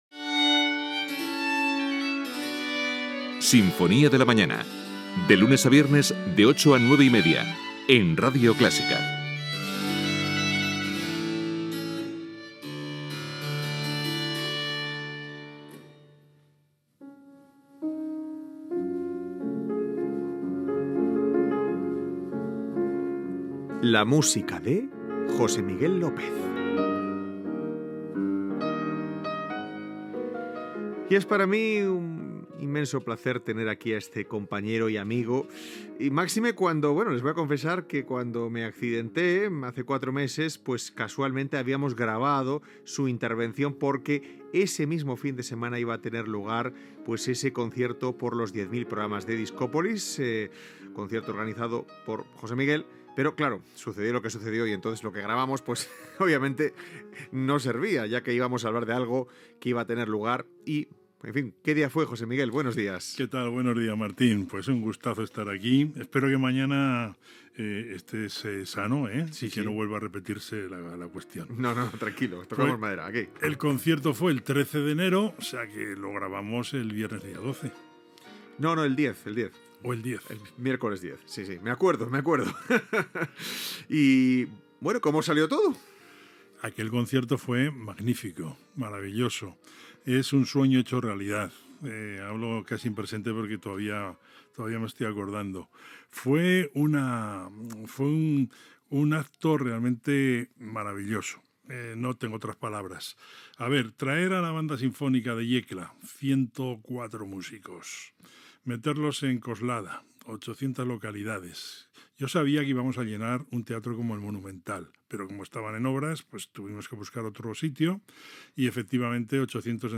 Indicatiu del programa
Gènere radiofònic Musical